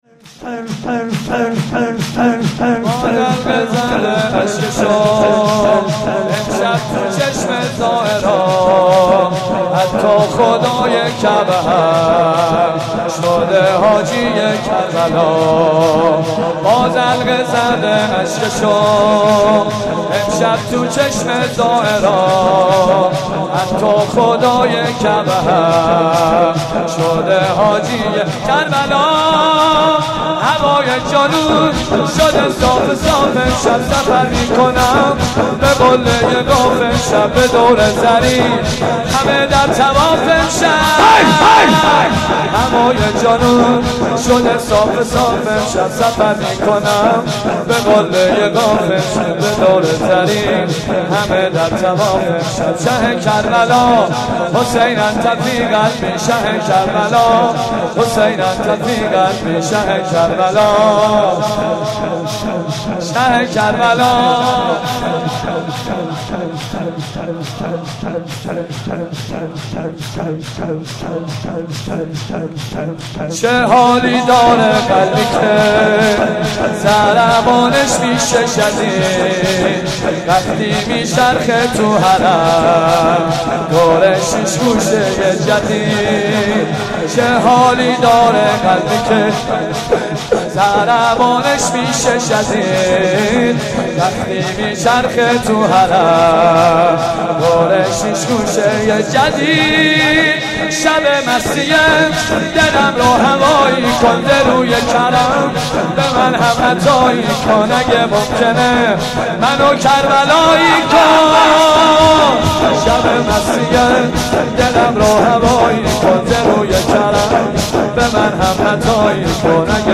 05.sineh zani.mp3